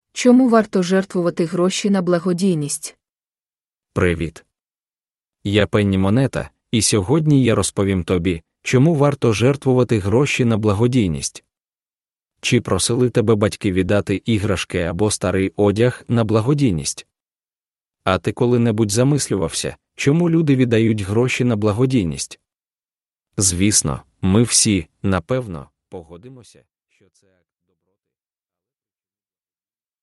An AI narrated short financial story for children explaining why you should give money to charity if you can.